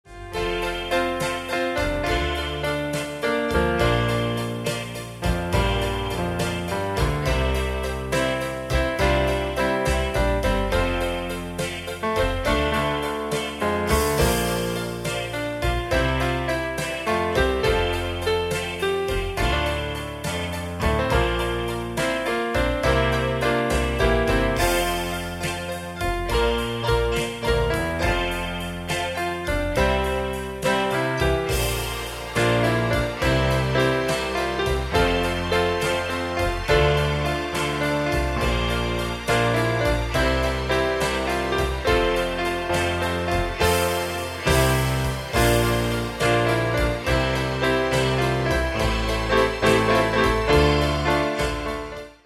4:08 50's Ballad